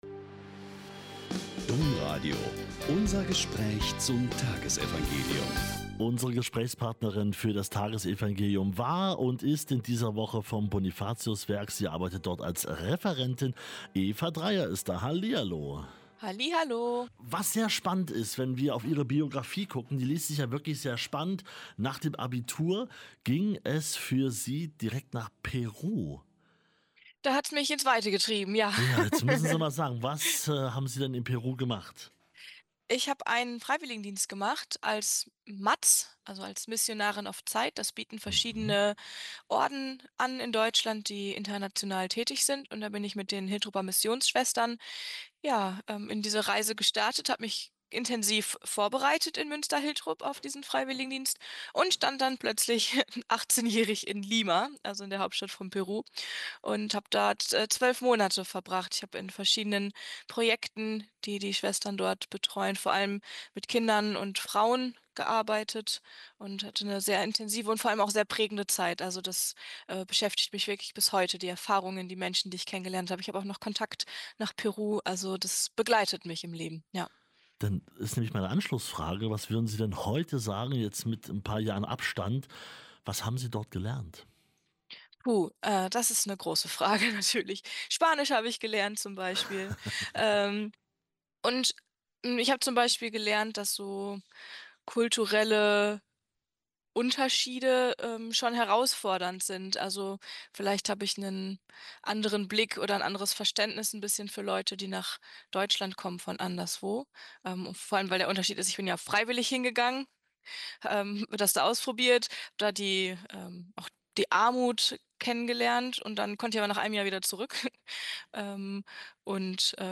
Lk 18,1-8 - Gespräch